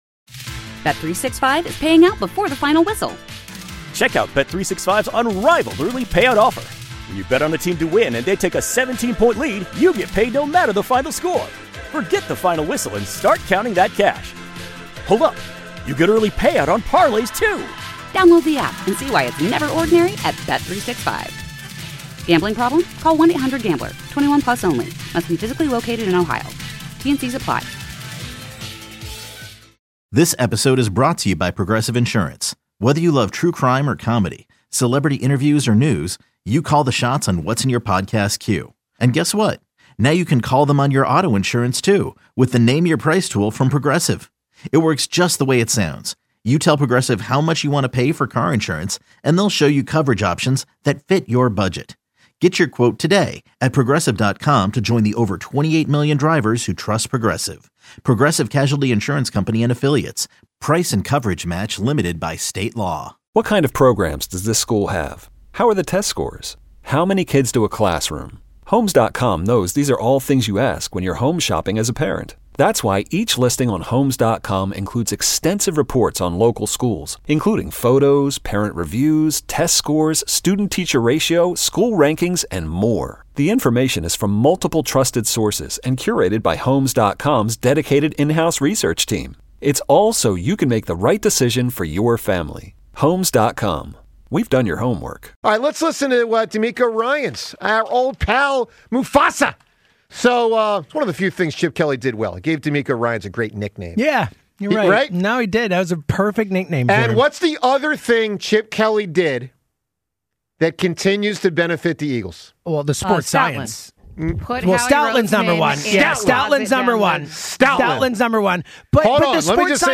The 94WIP Morning Show delivers everything Philly sports fans crave — passionate takes, smart analysis, and the kind of raw, authentic energy that defines the city.
This is where the voice of the Philly fan is heard loud and clear.
You can catch the 94WIP Morning Show live on SportsRadio 94WIP weekdays from 6–10 a.m. ET.